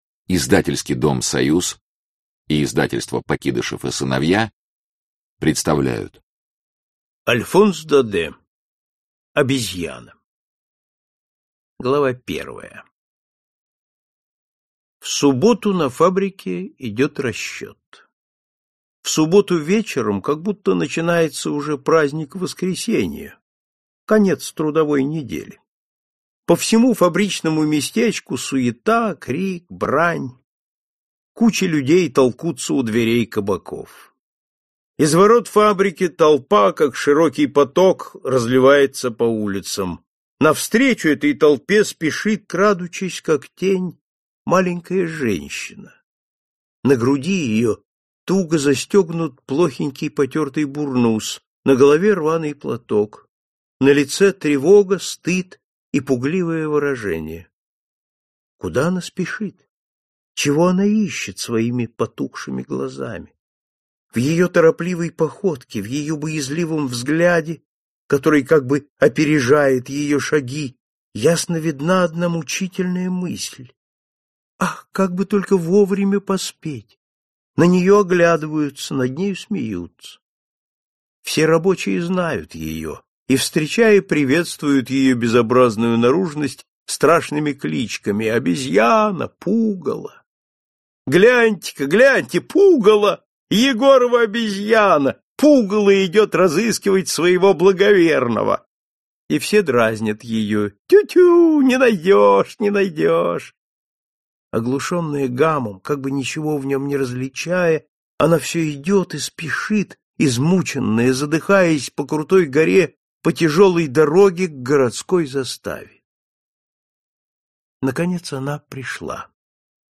Аудиокнига Тайна старого мельника и другие рассказы | Библиотека аудиокниг